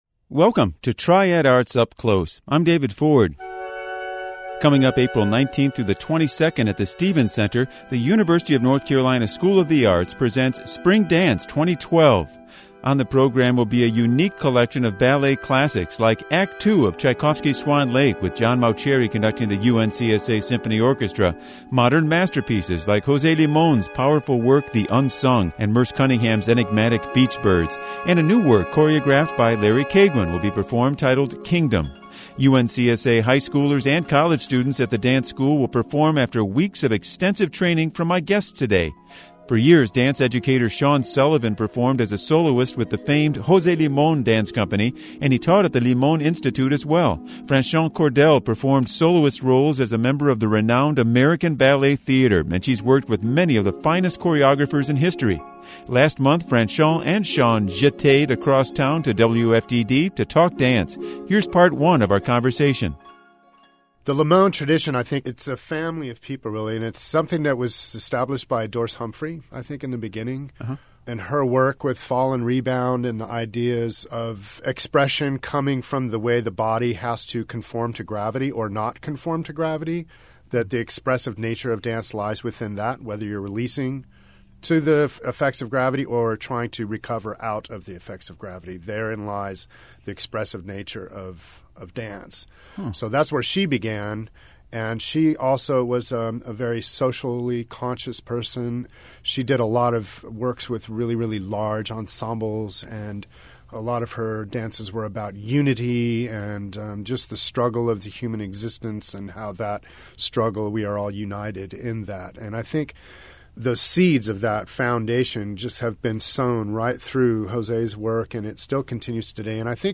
Listen (mp3)Listen to the program as broadcast on April 5, 2012